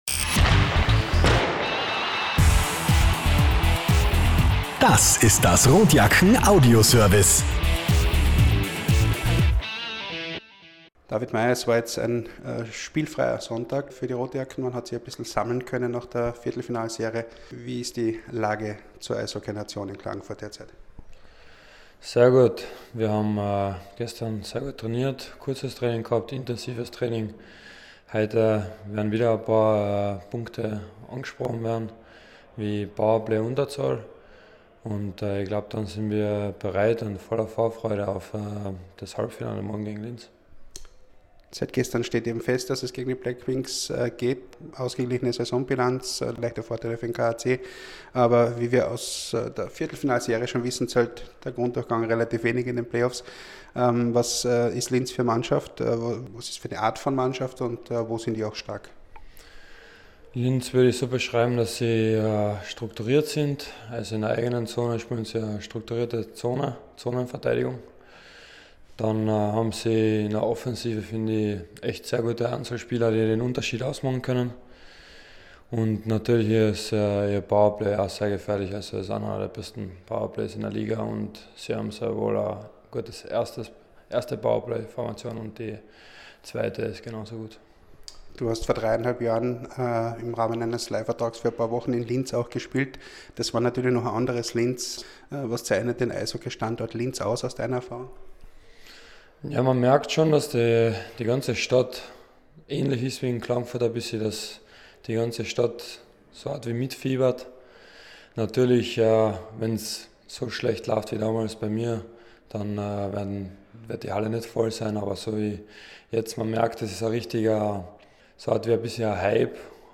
Pre-Game-Kommentar: